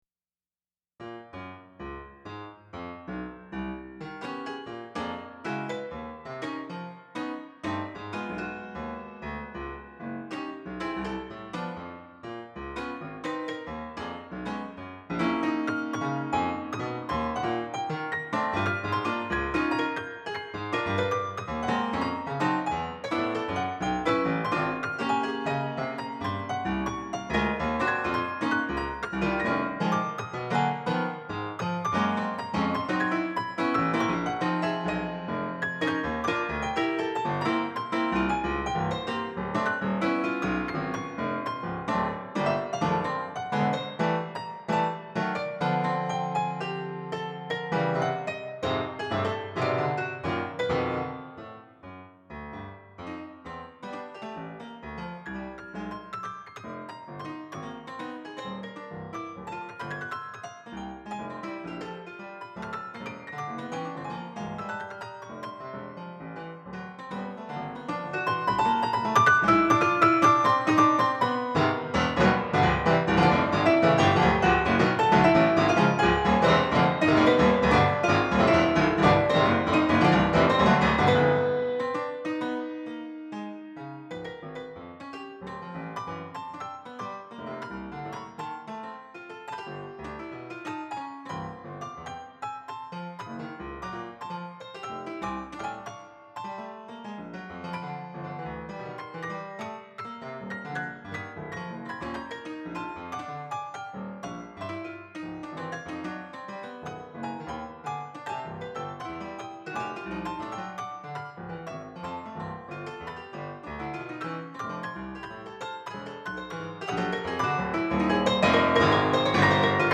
You know, I’m sitting here in my office doing creative work on Digital Performer, and I’ve had a couple of Nancarrow queries lately from people doing intensive analytical work on him, and it occurs to me that I’ve got all these Nancarrow player piano rolls as MIDI information on my computer, including more than 60 that were found in his studio that don’t correspond to the canonical studies (and I do mean canonical, not canonic).
The first three sound like finished pieces.